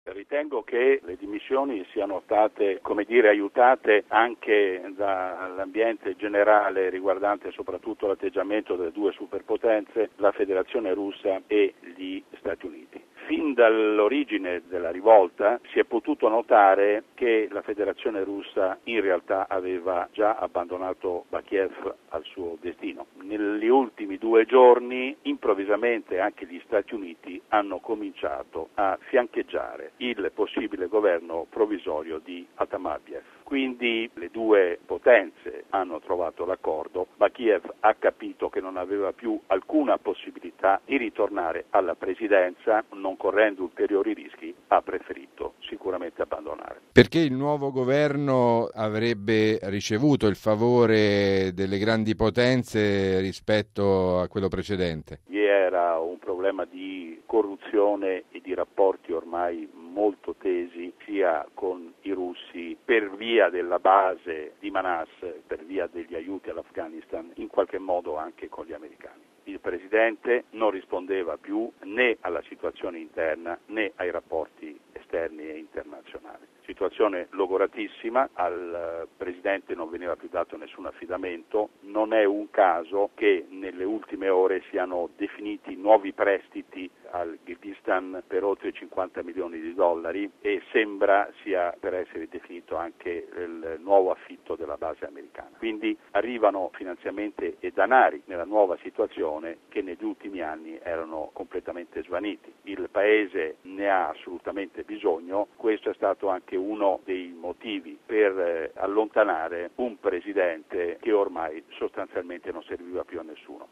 docente di Studi Strategici